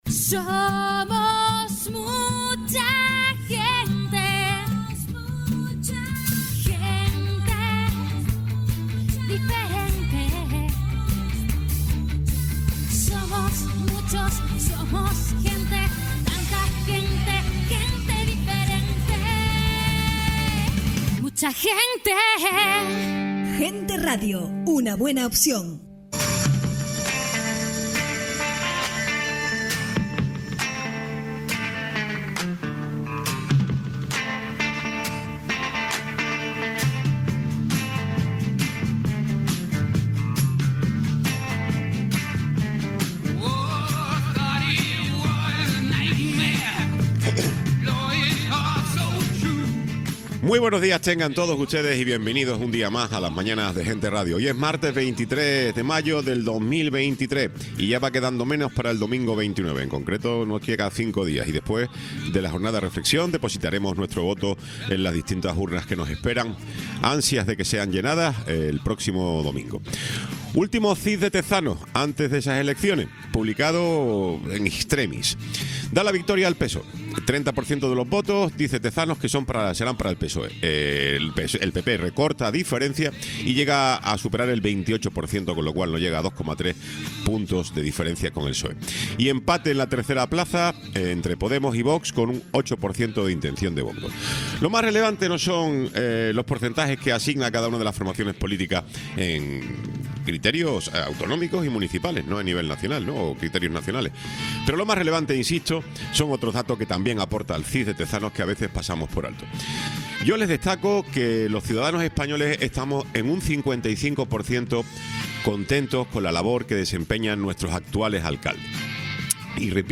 Tiempo de entrevista con Francisco Linares, Alcalde de La Orotava